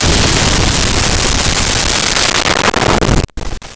Explosion Large 5
Explosion Large_5.wav